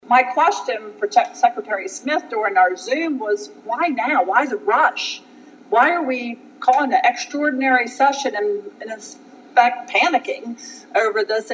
That was State Representative Valerie Jones Giltner with her comments and question.
Extraordinary-Session-2.mp3